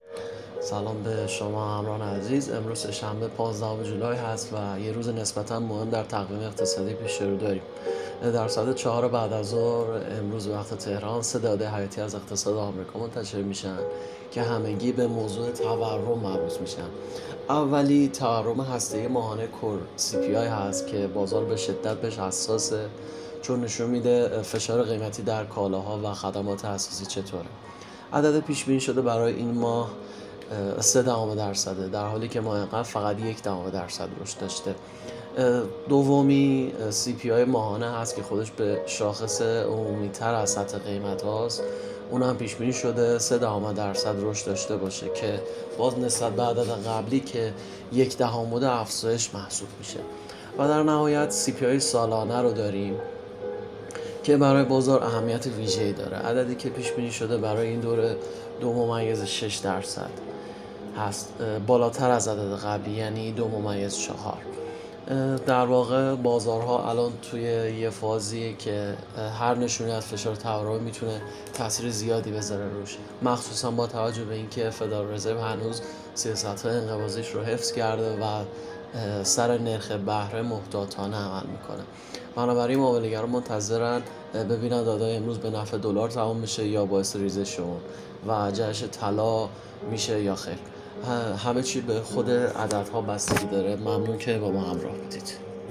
🔸گروه مالی و تحلیلی ایگل با تحلیل‌های صوتی روزانه در خدمت شماست! هدف اصلی این بخش، ارائه تحلیلی جامع و دقیق از مهم‌ترین اخبار اقتصادی و تأثیرات آن‌ها بر بازارهای مالی است.